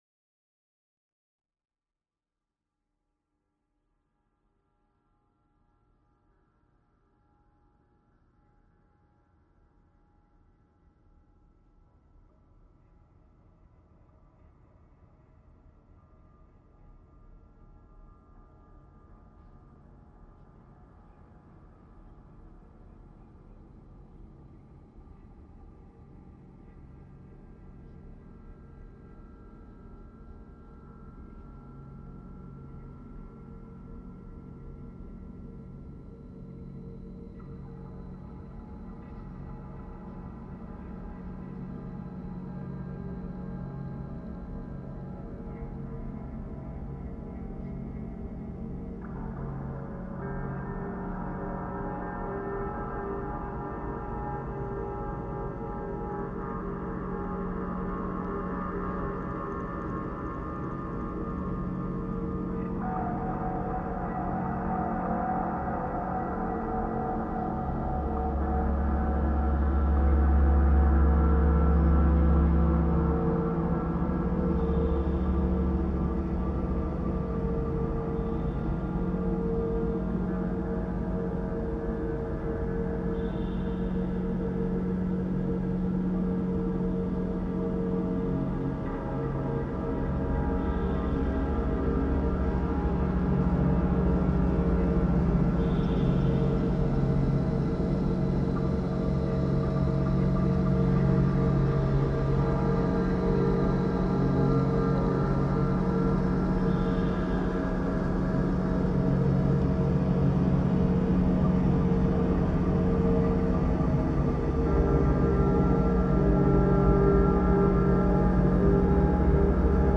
Drone Music / Ambient / Dark Ambient